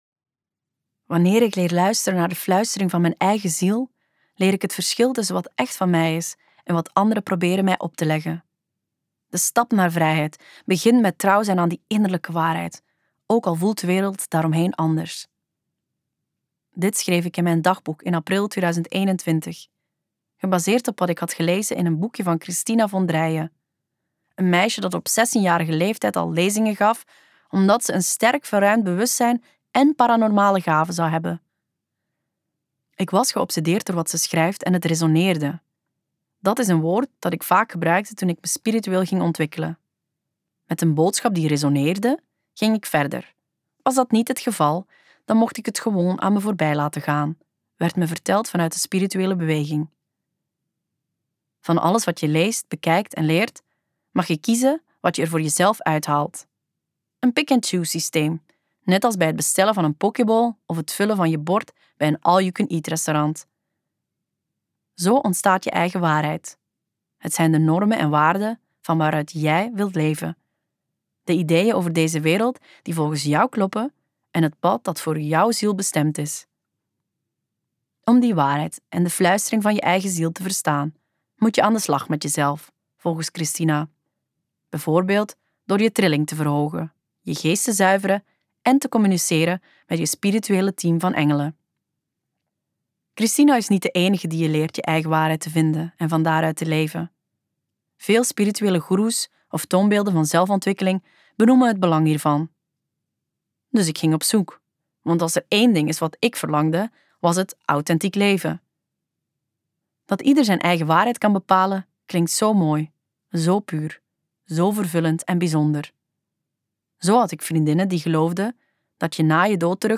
KokBoekencentrum | De leugens die we graag geloven luisterboek
Luister het gratis fragment De leugens die we graag geloven Tegenover de Waarheid die werkelijke vervulling brengt Auteur